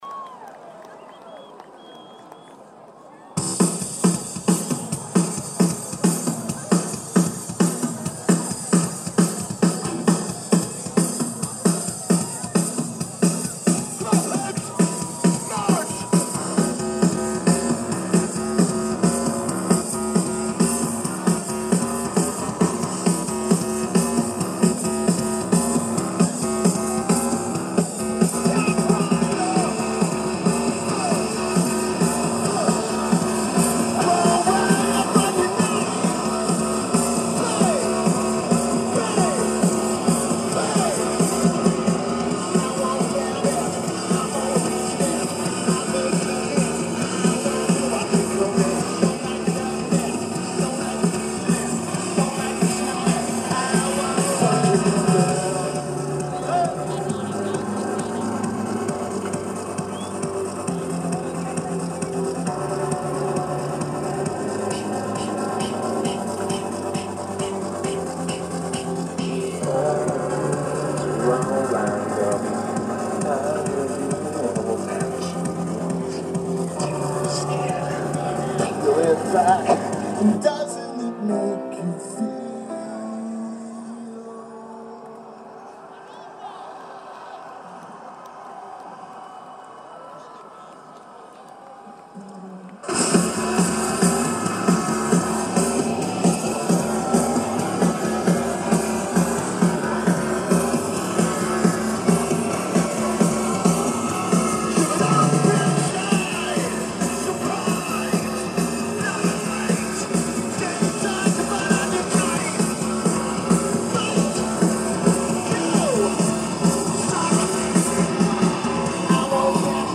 Outside Lands Festival
Lineage: Audio - AUD (Sony PCM-M10 + Internal Mics)